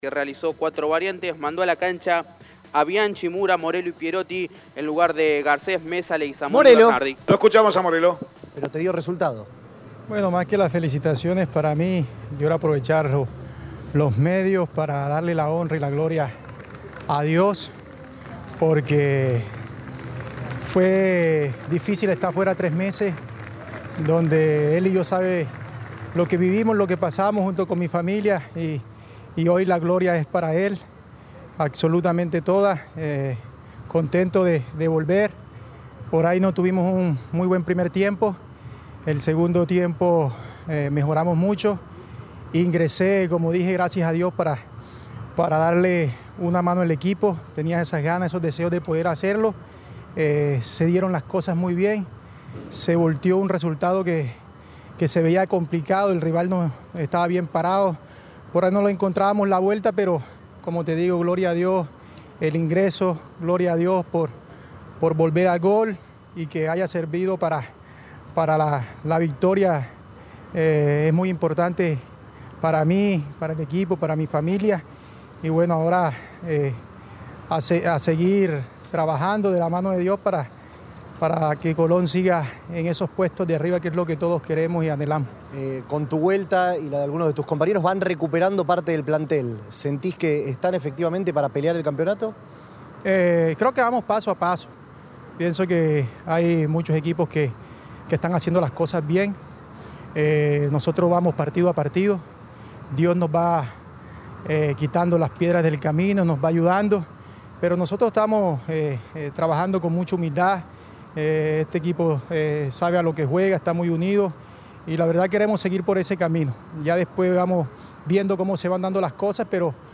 Escuchá la palabra del delantero de Colón